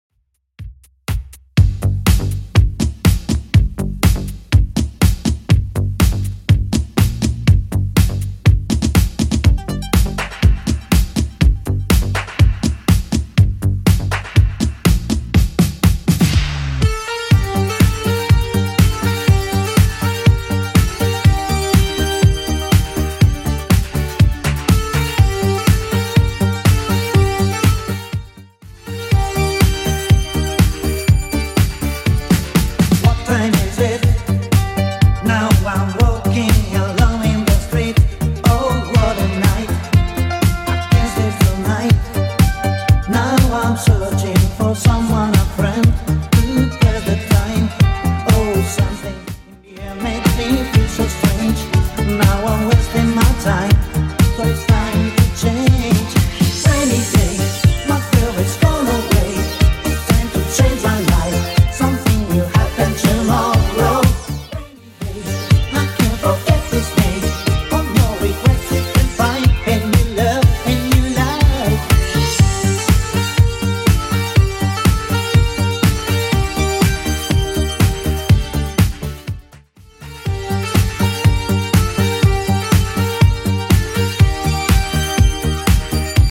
Genre: 80's Version: Clean BPM: 126